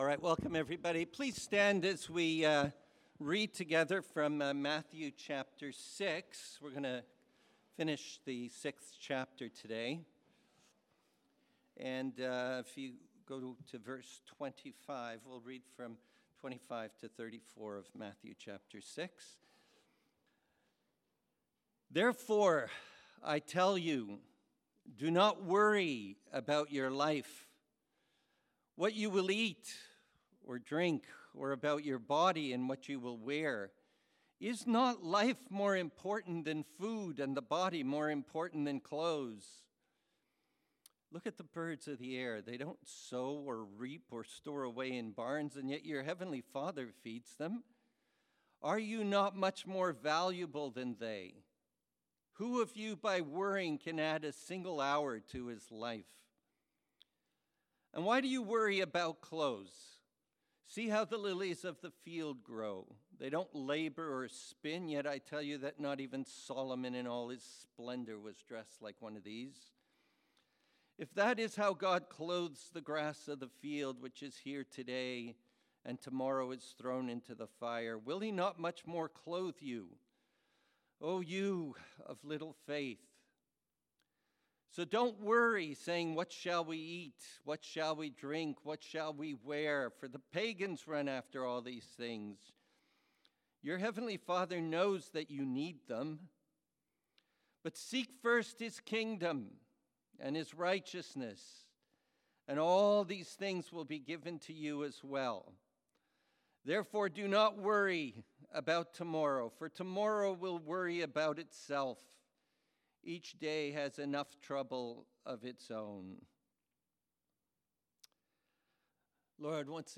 Sermons | Mountainview Christian Fellowship